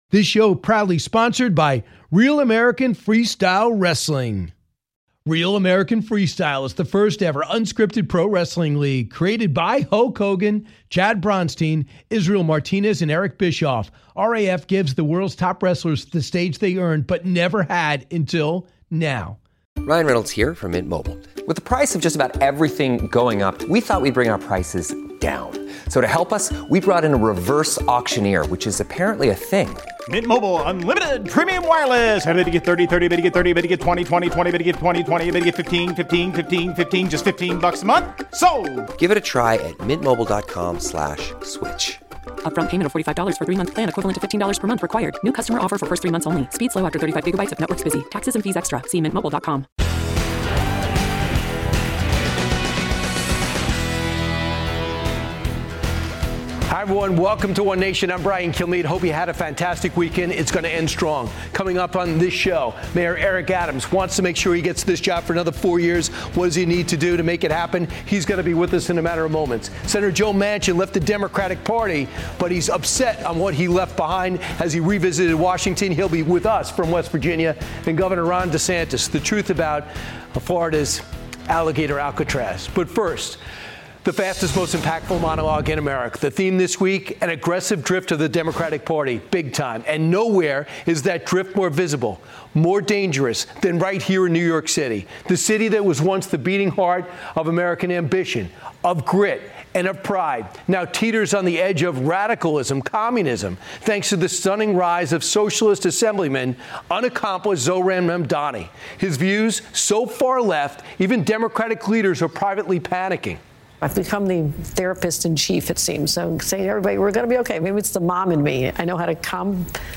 One Nation, hosted by Brian Kilmeade, airs on the Fox News Channel on Sunday nights at 10 PM ET.